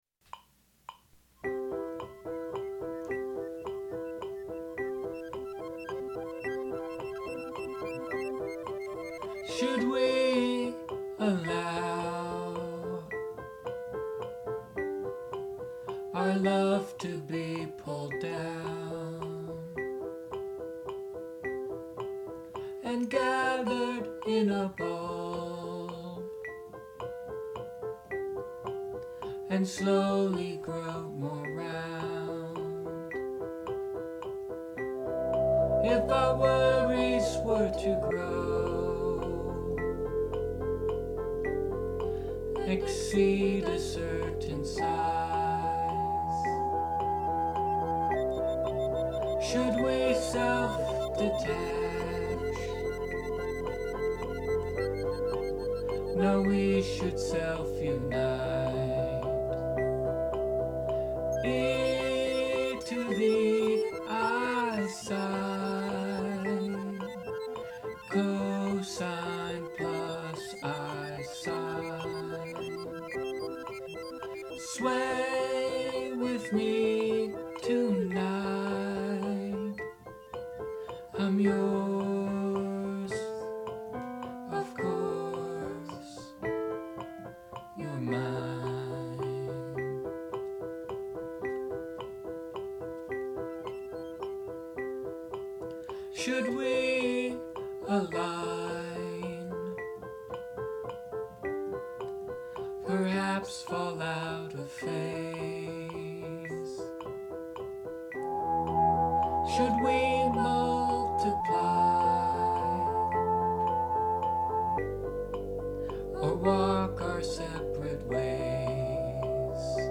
C, F, G
verse, verse, chorus, verse, verse, chorus